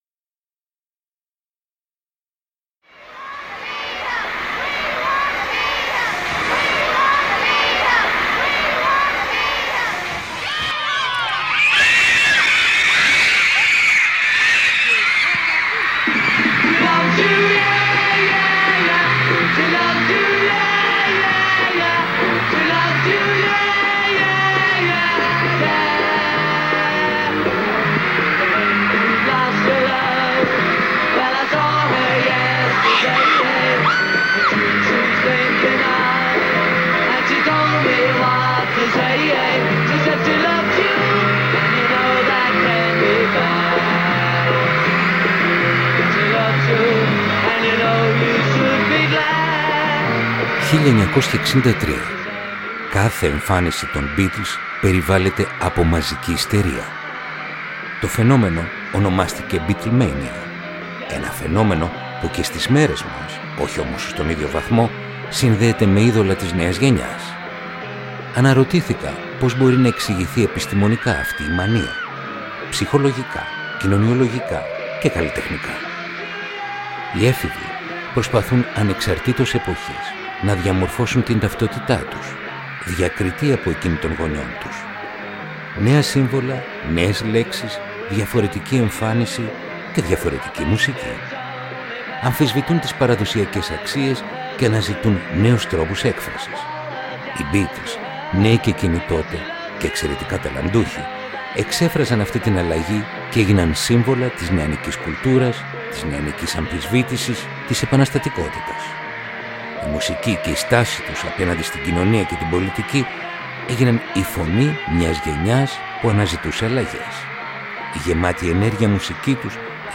Ρομαντικά κοντσέρτα για πιάνο – Επεισόδιο 41ο